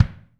BD 1M.wav